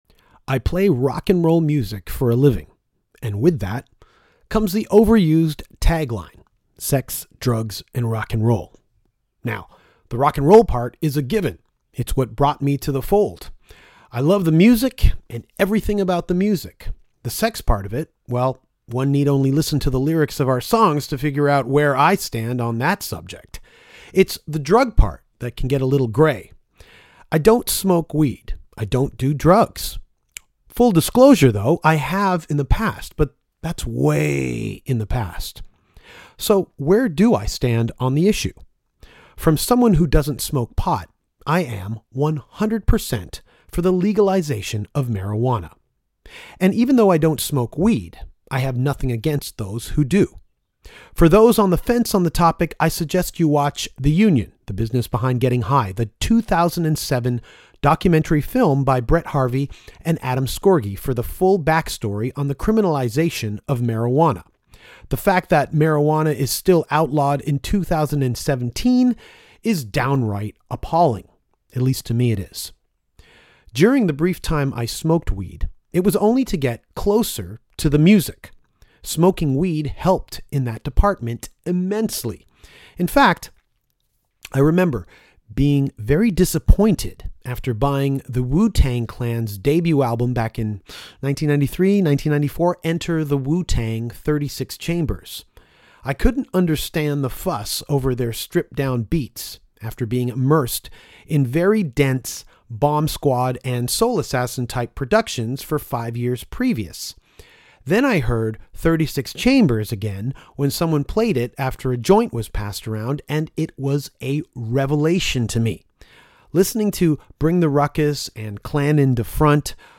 onto the podcast at the Greenhouse Vapour Lounge to discuss all things marijuana. With the legalization of marijuana in Canada just around the corner, it’s a subject on everyone’s mind …